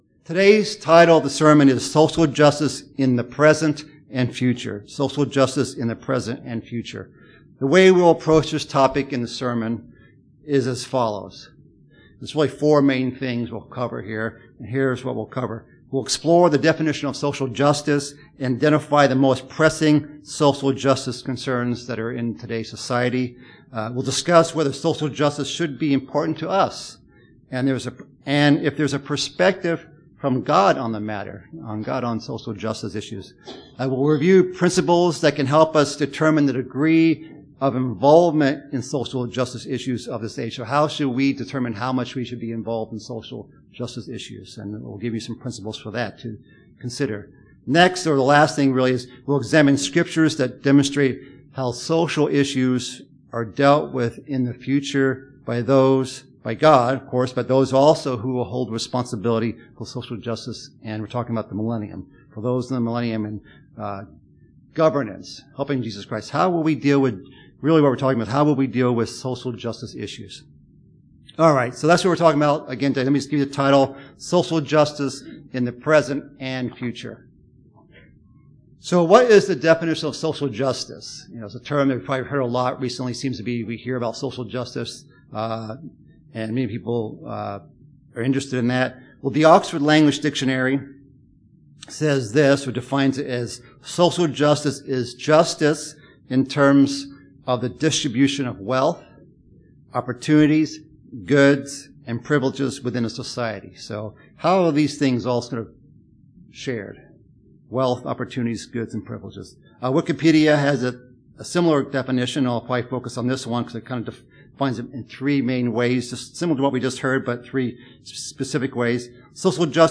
Sermon about social justice in the present and future.